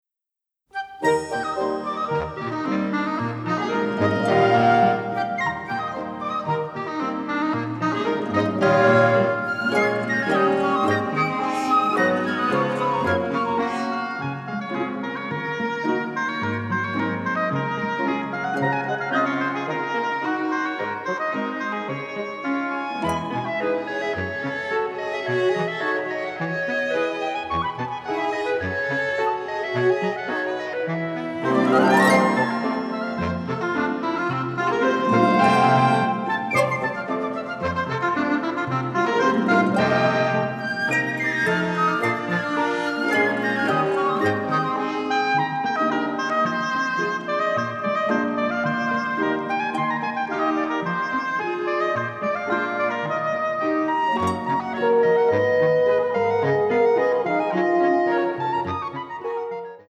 mostly in stereo, with some cues in mono